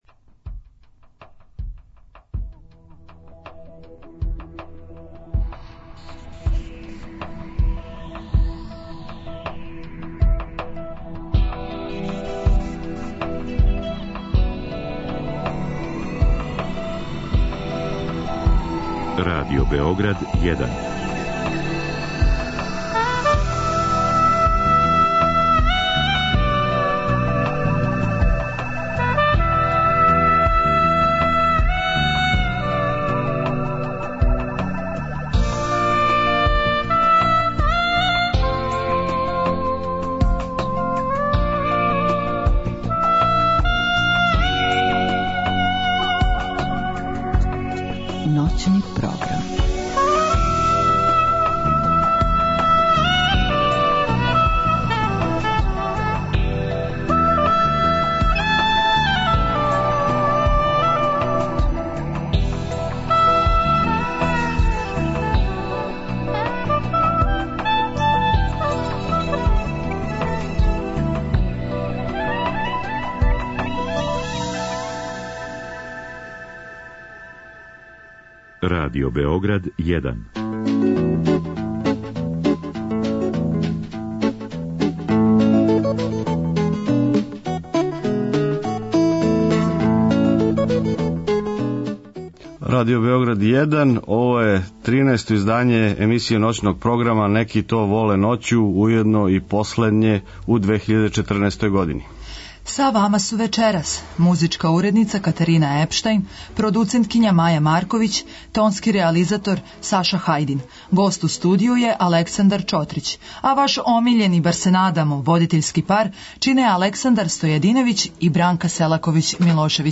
Са гостом ћемо разговарати о занимљивом споју хумора и политичког ангажовања. Имаћете прилику да послушате најсвежије афоризме али и чујете како публика у дијаспори реагује на њих.